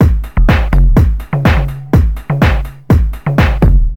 描述：高端电子管的声音，清脆的grooveloop
Tag: 124 bpm Dance Loops Groove Loops 681.09 KB wav Key : Unknown